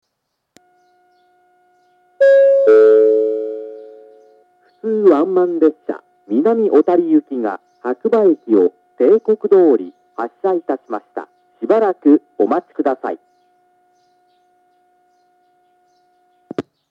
１番線下り白馬駅発車案内放送 普通ワンマン南小谷行の放送です。